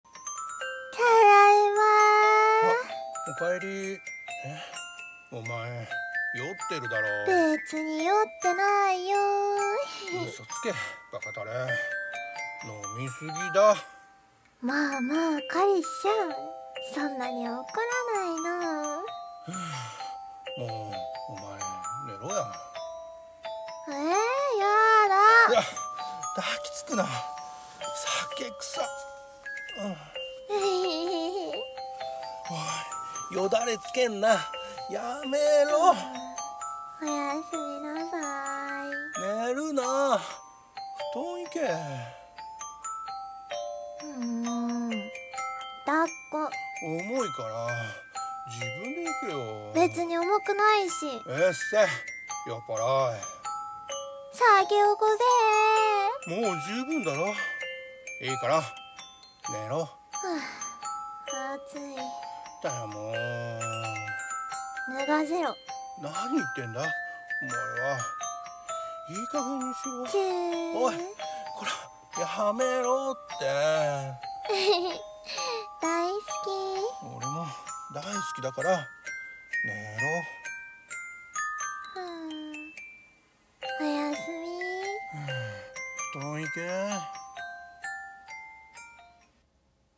【声劇】酔っ払い彼女